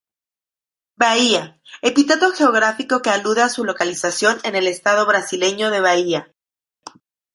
lo‧ca‧li‧za‧ción
/lokaliθaˈθjon/